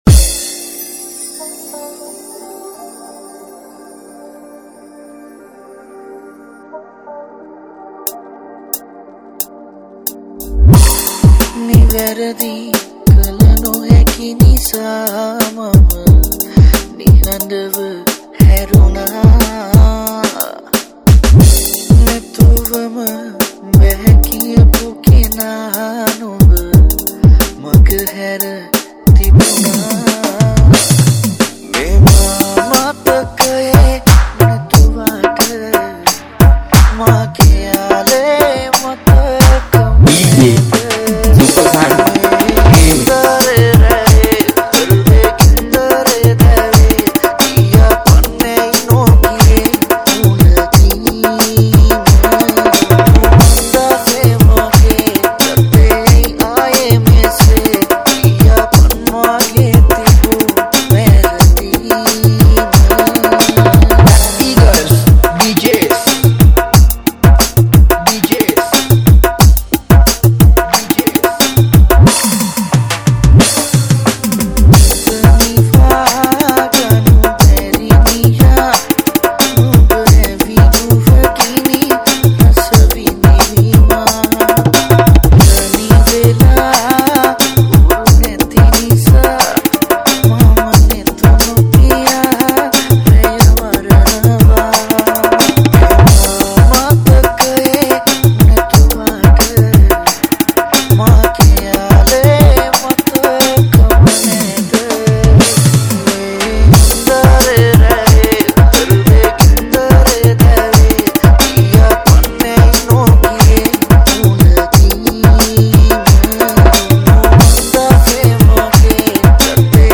Category: Dj Remix